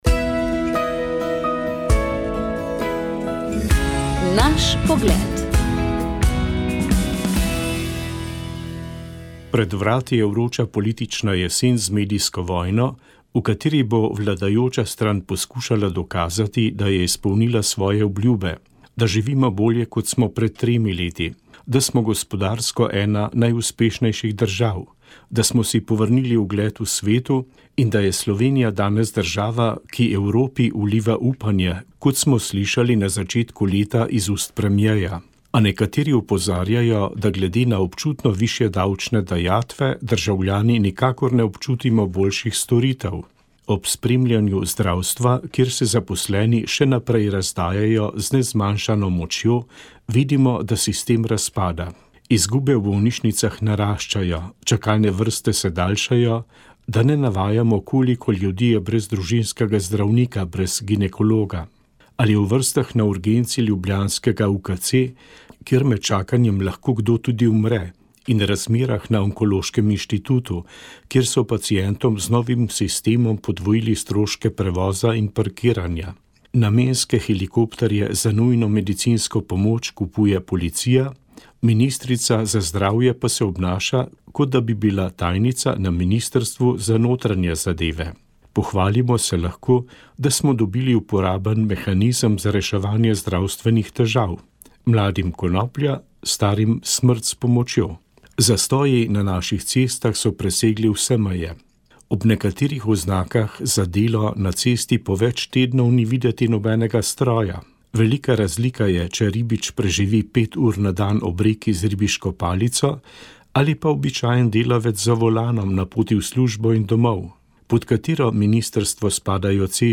Tokrat smo iskali točke, do katerih nas pripeljejo znanje in izkušnje iz orientacije. Slovenske ekipe so na balkanskem prvenstvu v planinski orientaciji, ki ga je od 8. do 10. novembra gostil srbski Rajac, dosegle dve prvi, dve drugi in dve tretji mesti ter ekipni pokal za skupno drugo mesto. Pred mikrofon smo povabili mlade tekmovalce iz Planinskega društva Polzela